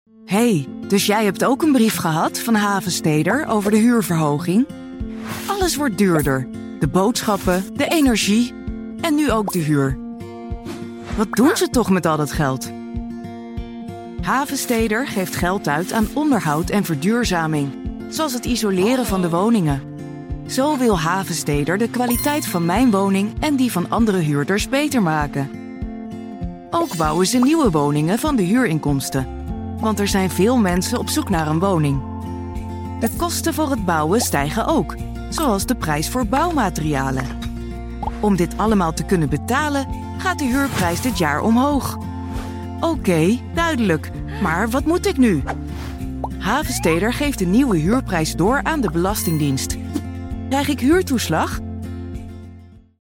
Vídeos Corporativos
Sou uma locutora profissional holandesa com uma voz clara, calorosa e fresca.